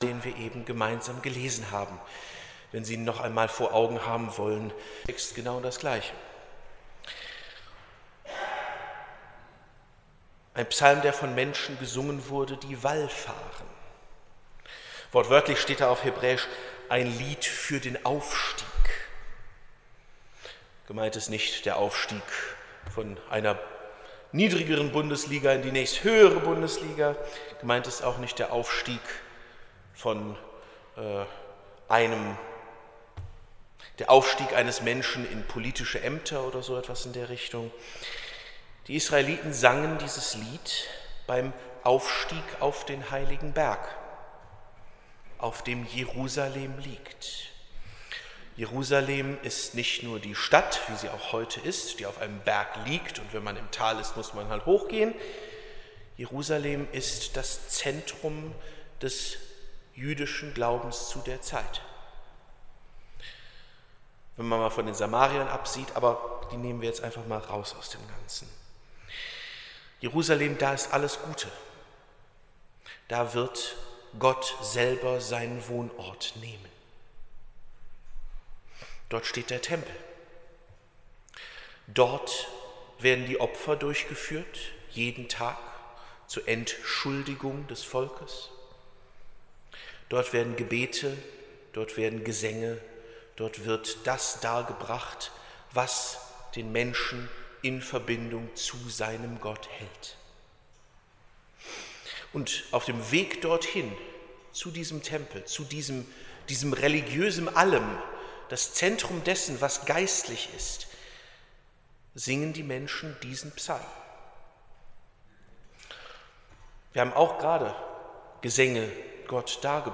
24.11.2024 – Gottesdienst
Predigt (Audio): 2024-11-24_Wallfahrt.mp3 (19,0 MB)